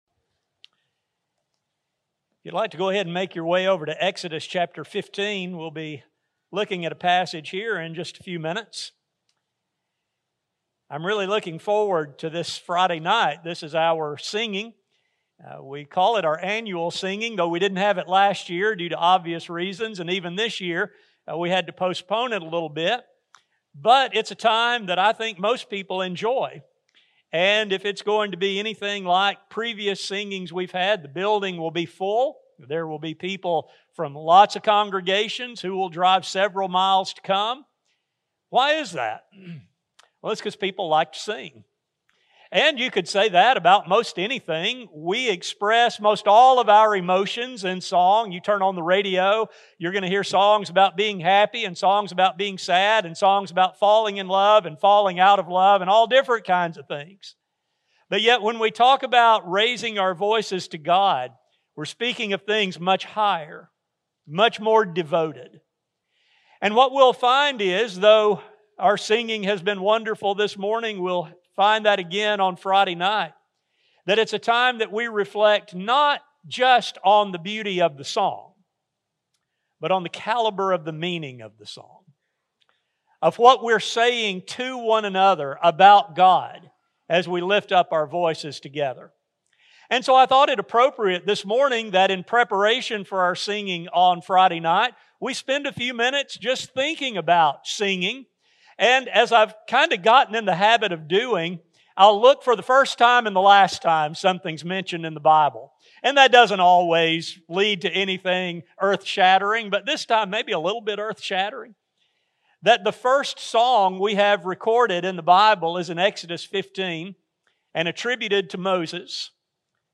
This study focuses on a better understanding of the Song of Moses and the Song of the Lamb and the lessons they teach to modern worshipers. A sermon recording